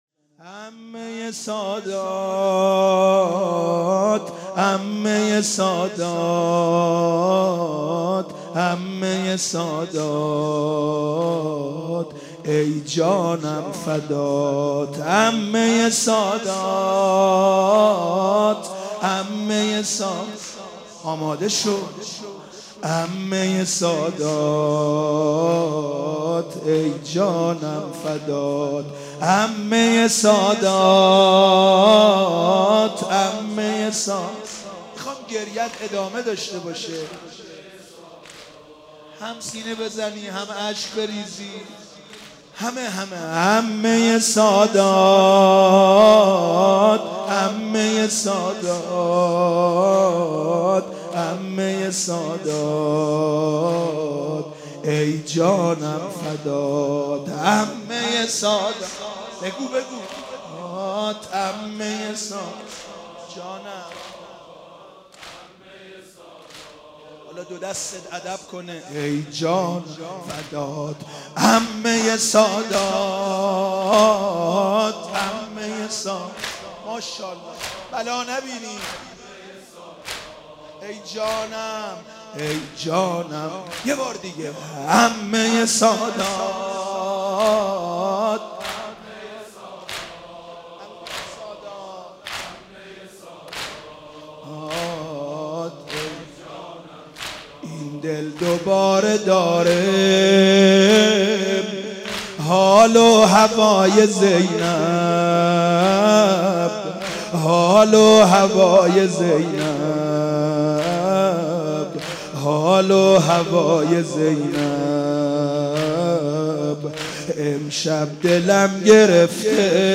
زمینه.mp3